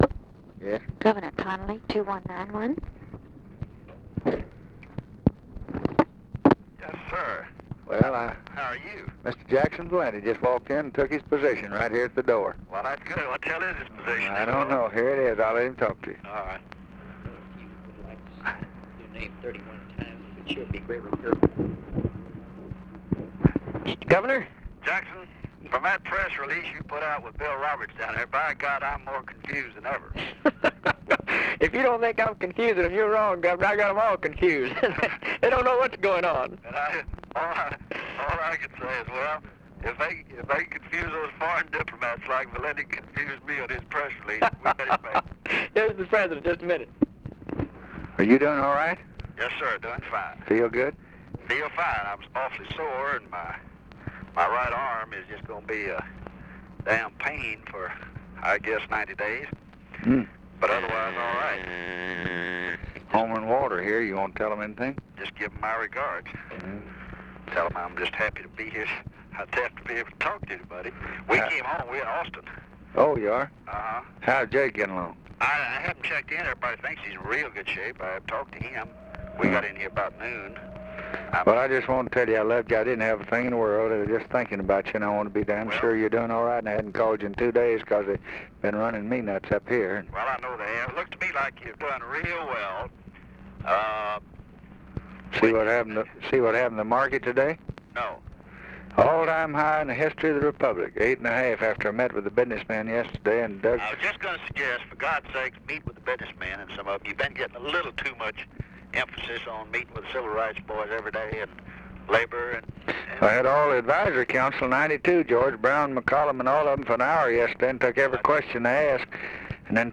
Conversation with JOHN CONNALLY, December 5, 1963
Secret White House Tapes